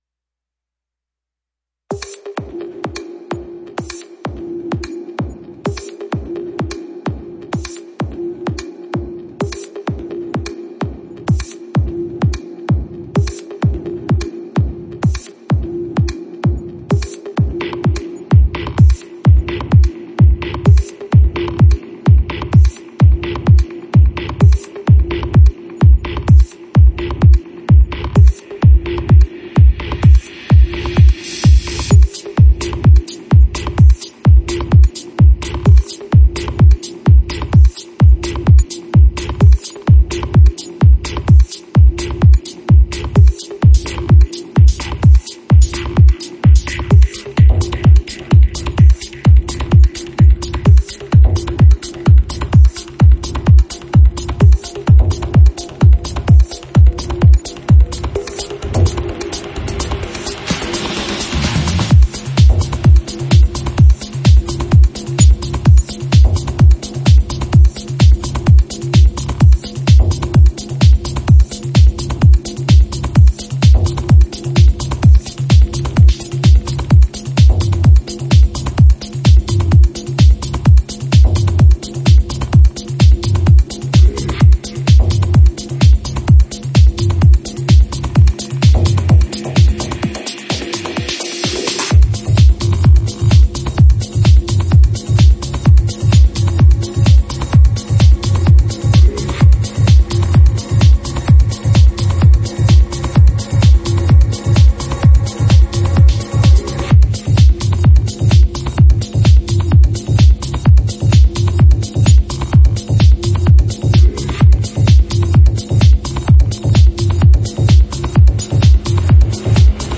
now with three power techno tracks